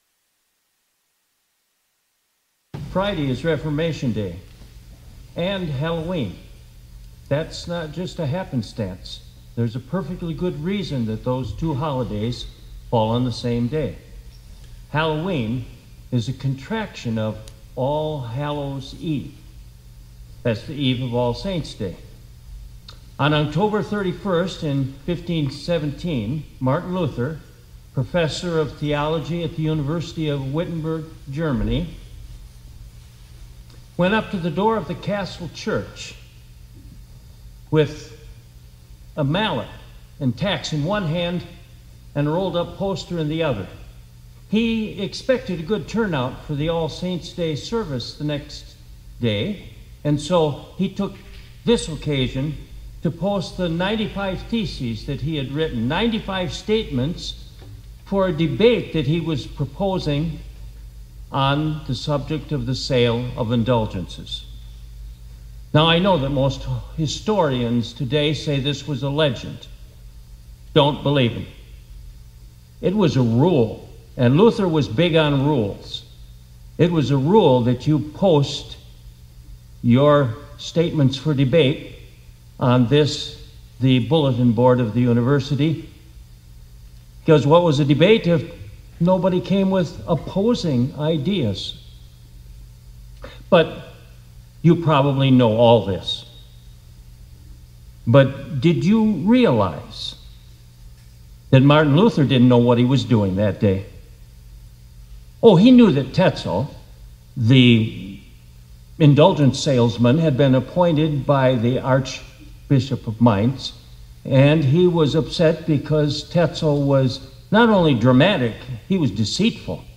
2025-10-29 ILC Chapel — God Knew What He Was…